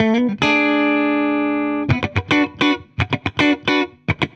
Index of /musicradar/dusty-funk-samples/Guitar/110bpm
DF_70sStrat_110-B.wav